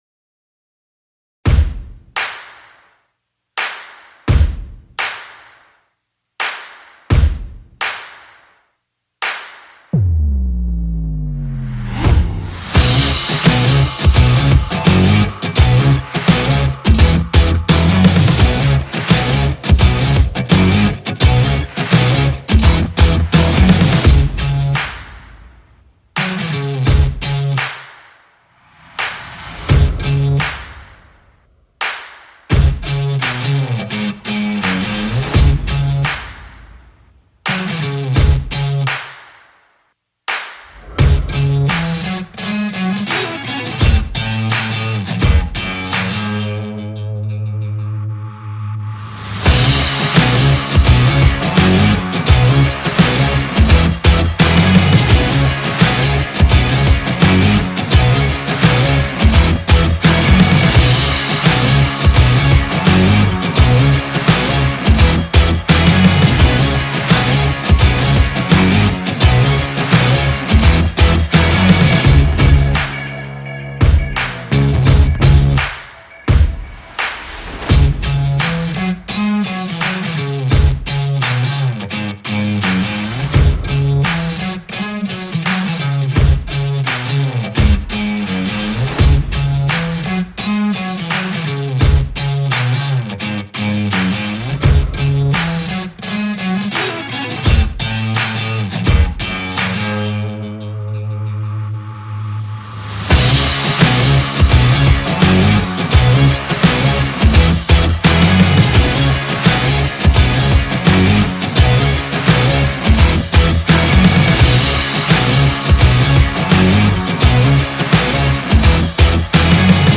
tema dizi müziği, heyecan aksiyon enerjik fon müziği.